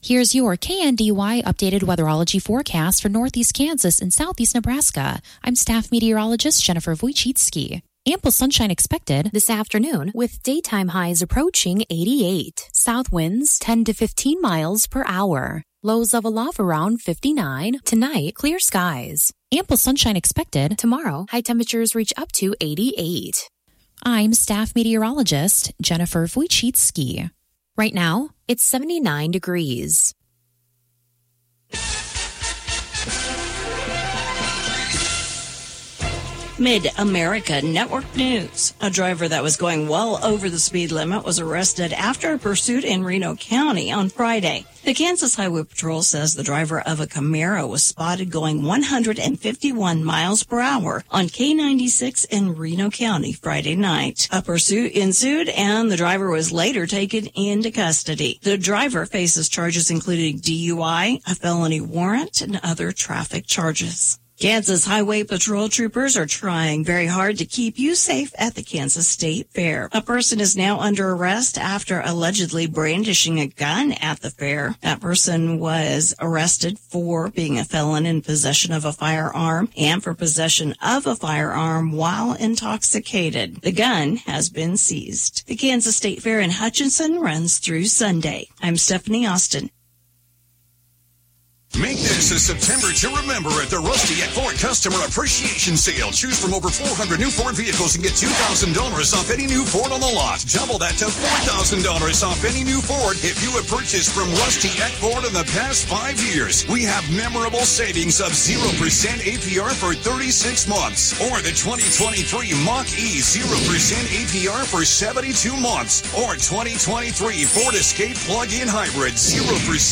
KNDY Midday News - 9/9/2024